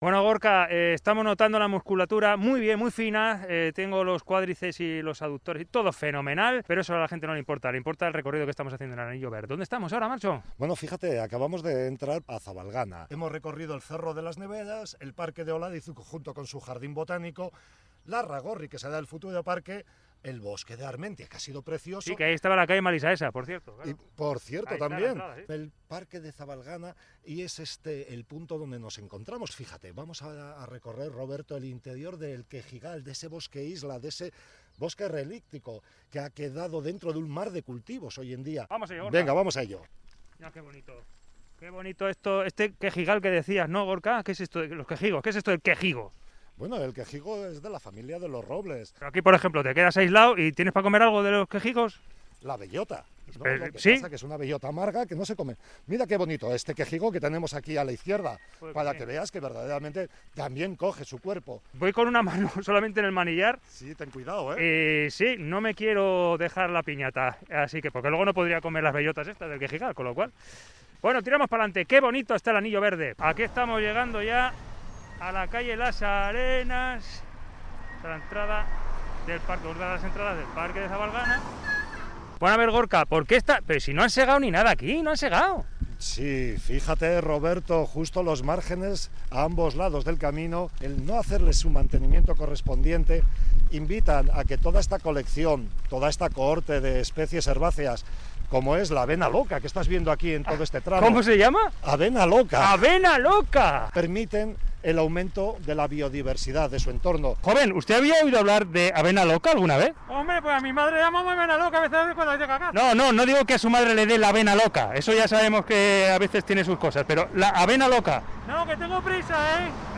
Audio: Reportaje: Vuelta en bici al anillo verde (y 2)